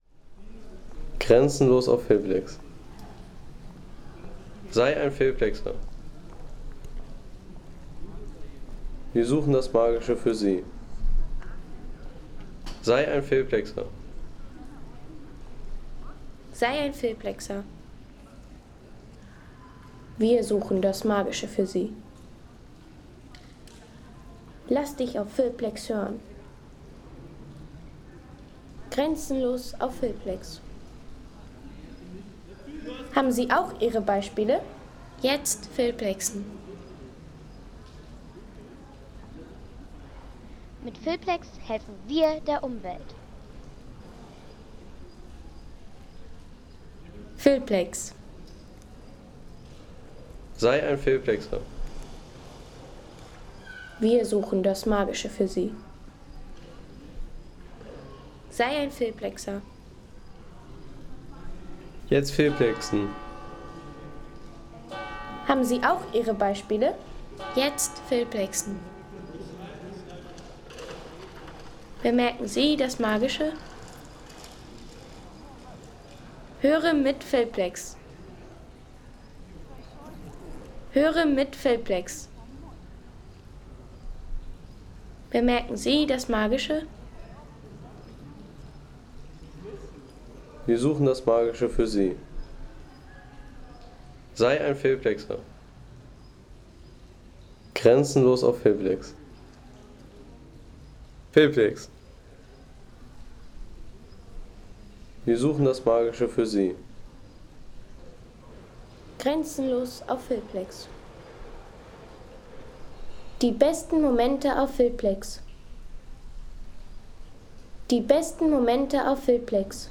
Innsbrucker Domplatz | Ruhige Stadtatmosphäre aus Österreich
Authentische Stadtatmosphäre vom Innsbrucker Domplatz in Österreich.
Eine ruhige urbane Klangkulisse aus Innsbruck mit barocker Platzatmosphäre für Filme, Reisevideos, Dokus und Sound-Postkarten.